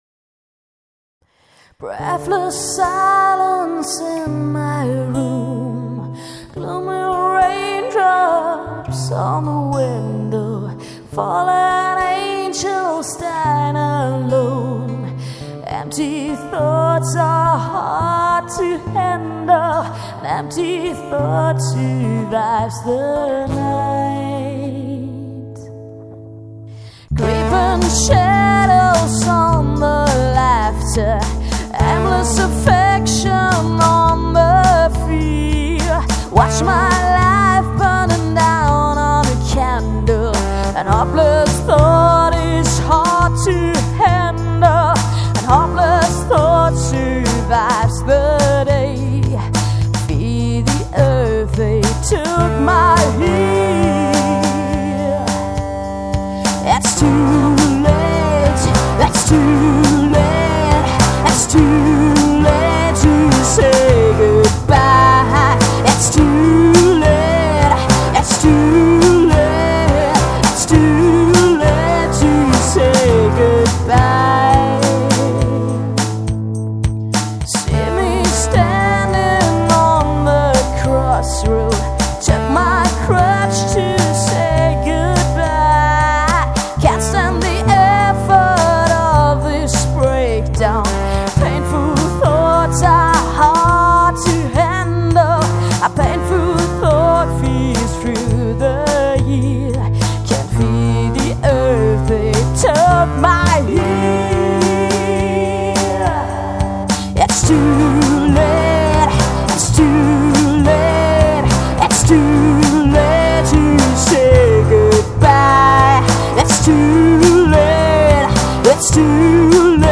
Pre-Production!